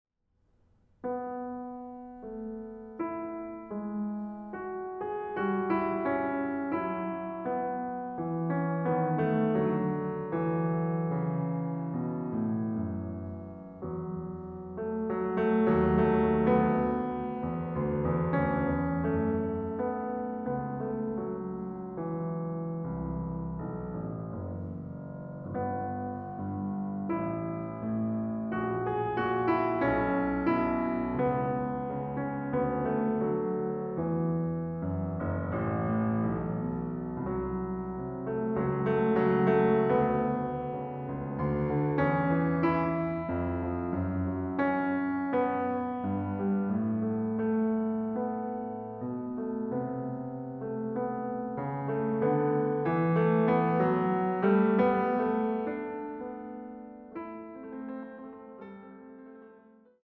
Klaviermusik